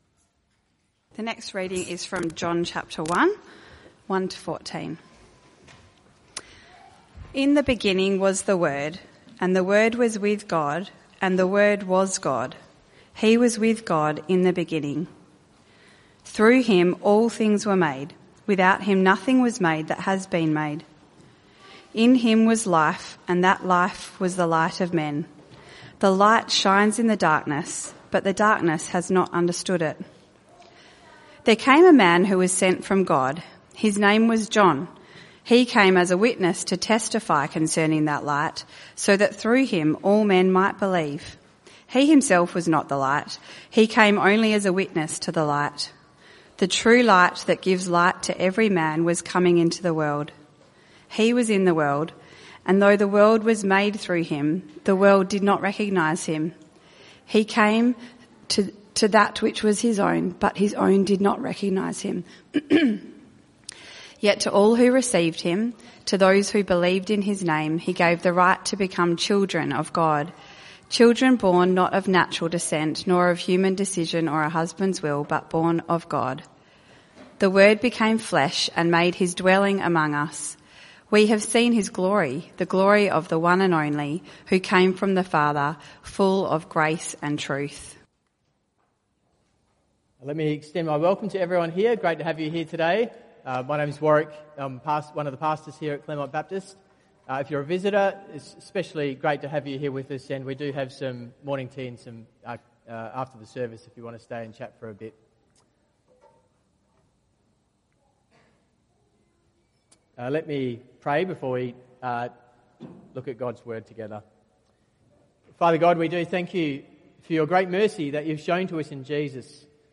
Passage: John 1:1-14 Type: Sermons